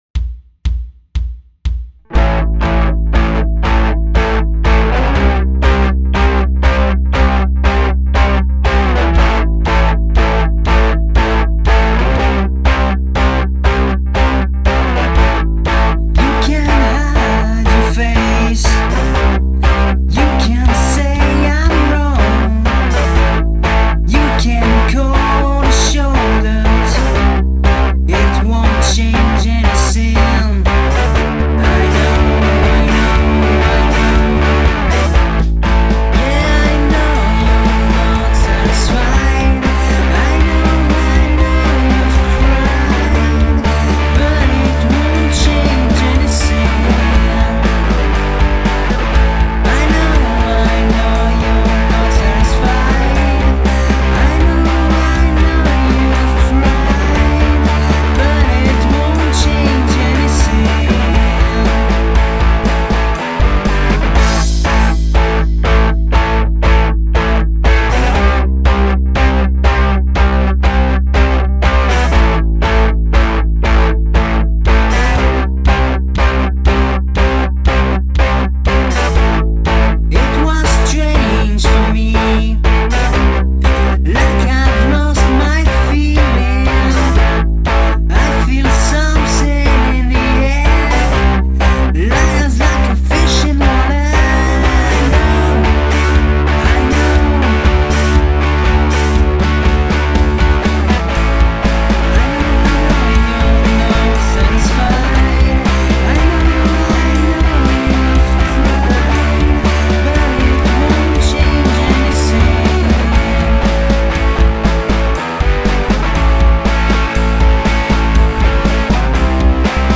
Boah, c'est que des bouts de trucs, une simple base de travail, avec une batterie midi pourrie (pas bossé dessus)... rien de défintif... mais puisque tu demandes :mrg:
En fait j'aime beaucoup l'ambiance seventies qui se dégage de ces morceaux.
pour moi aussi grand moment de rock'n'roll